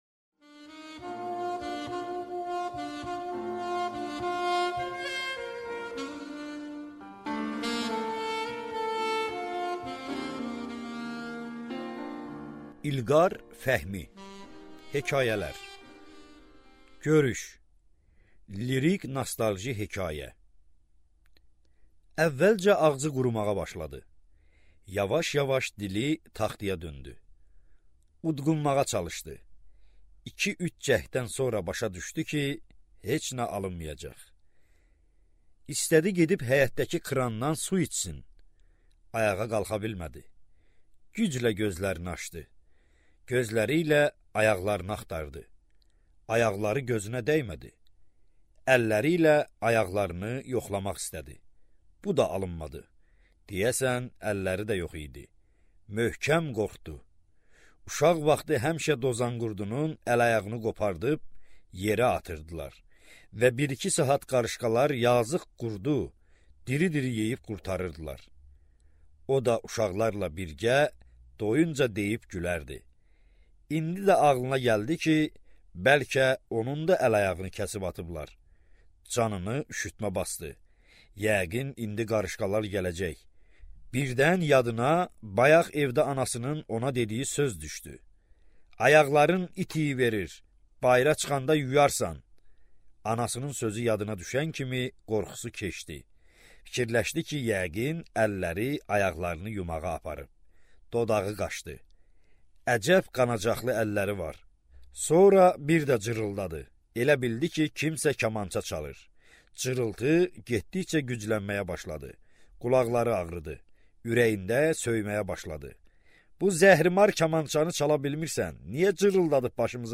Аудиокнига Nostalji | Библиотека аудиокниг